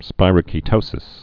(spīrə-kē-tōsĭs)